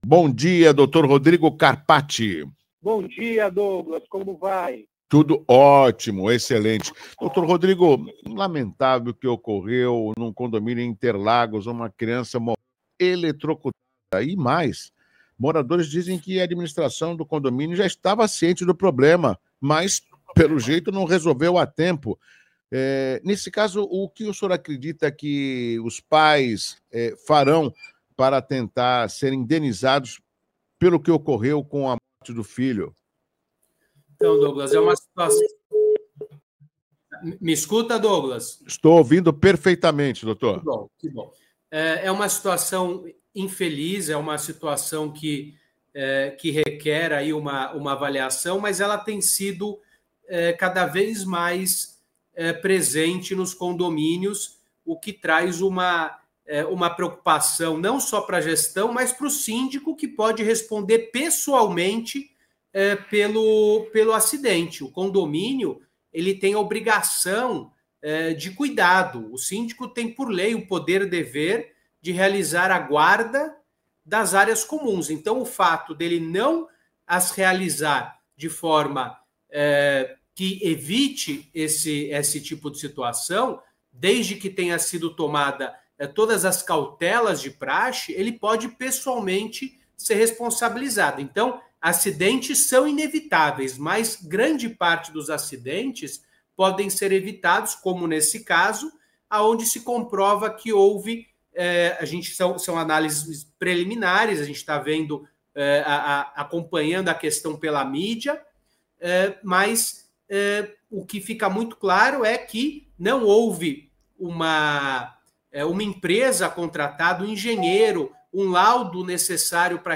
Em entrevista para a Rádio CBN Santos